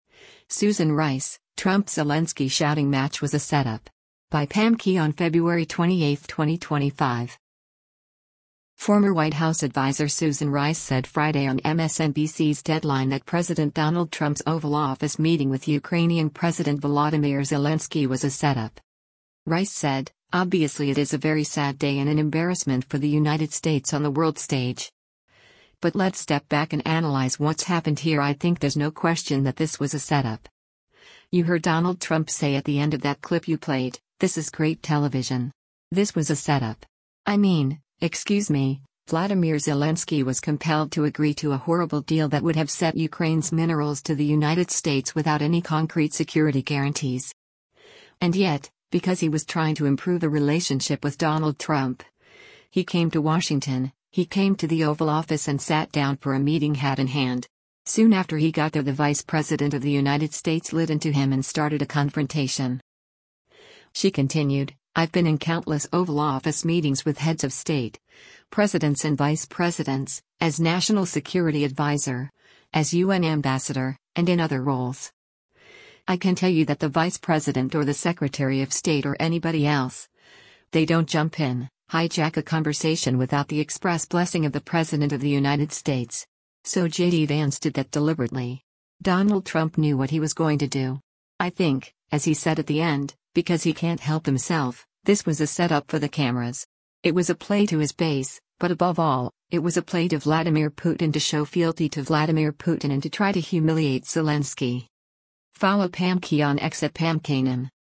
Former White House adviser Susan Rice said Friday on MSNBC’s “Deadline” that President Donald Trump’s Oval Office meeting with Ukrainian President Volodymyr Zelensky was a “setup.”